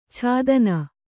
♪ chādana